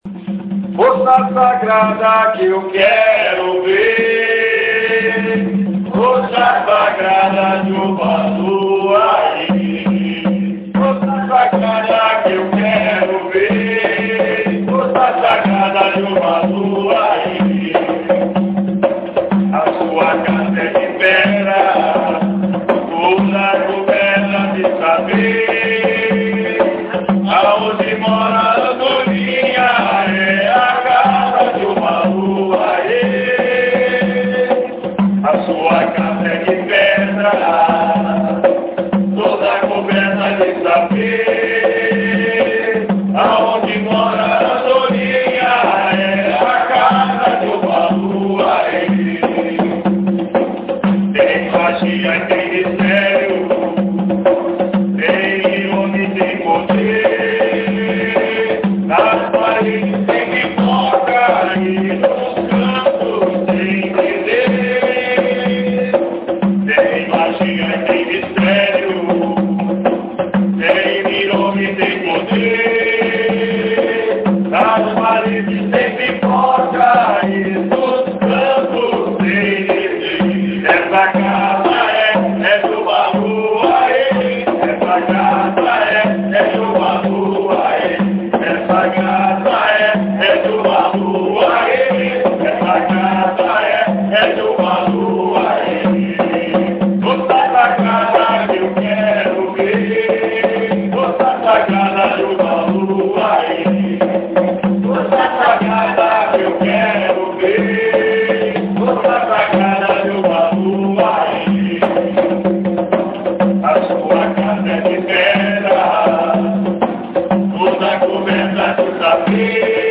Ensaio Gira – Casa Vó Maria